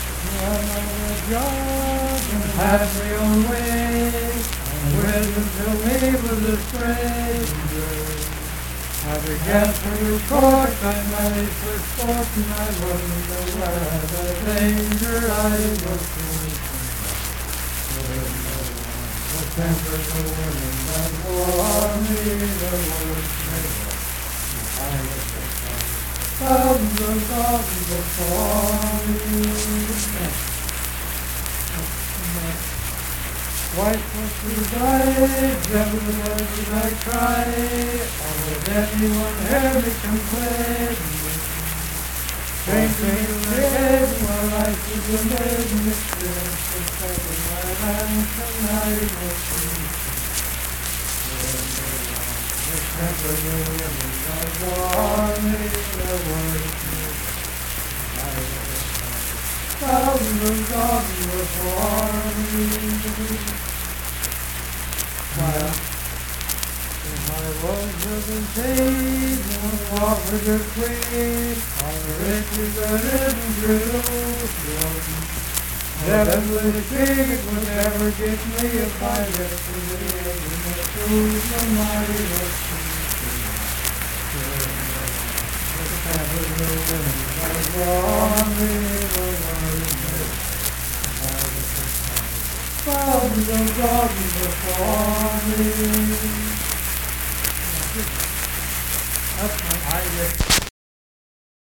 Unaccompanied vocal music
Richwood, Nicholas County, WV.
Miscellaneous--Musical
Voice (sung)